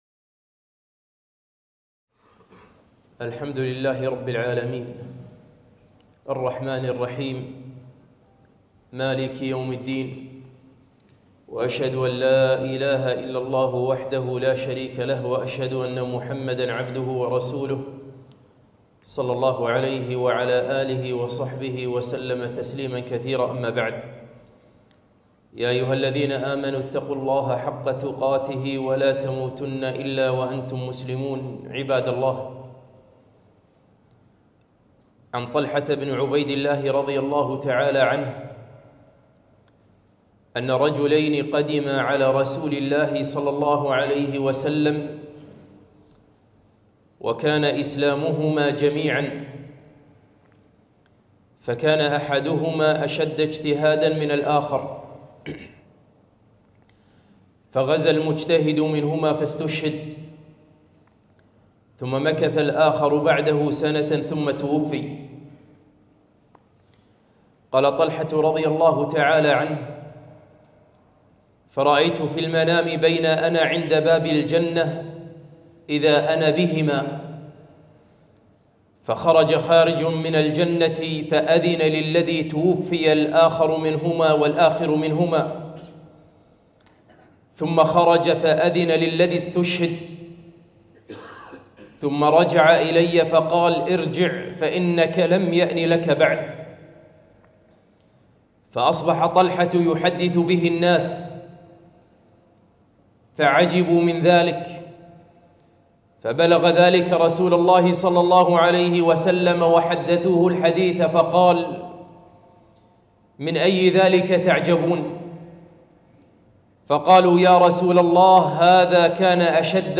خطبة - أدركه قبل الرحيل